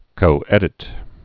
(kō-ĕdĭt)